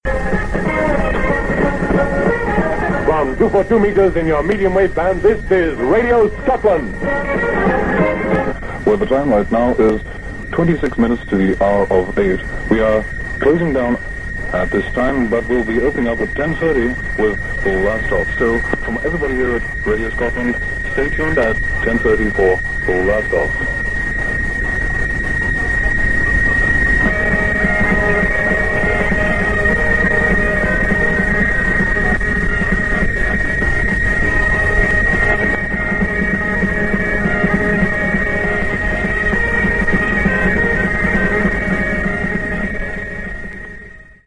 click to hear audio With the foreign interference very noticeable